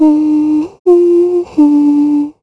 Ripine-Vox_Hum_kr.wav